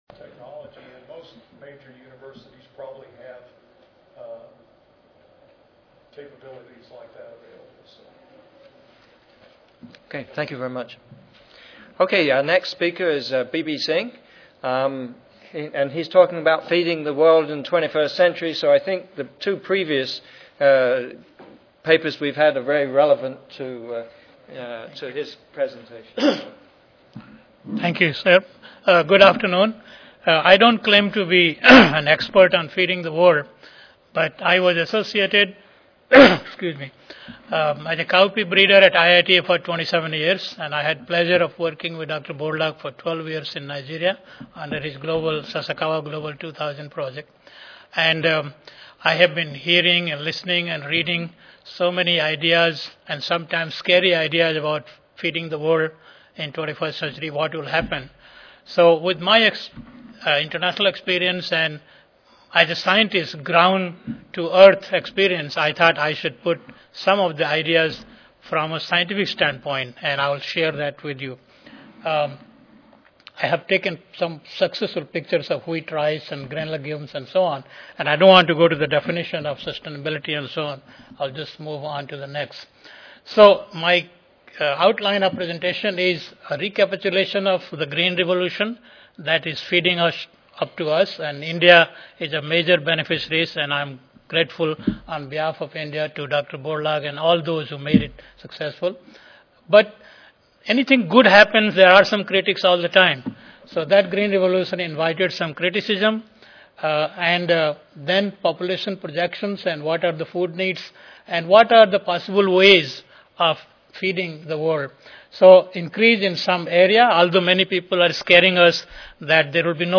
Abstract: Feeding the World in 21st Century � Challenges and Opportunities. (ASA, CSSA and SSSA Annual Meetings (San Antonio, TX - Oct. 16-19, 2011))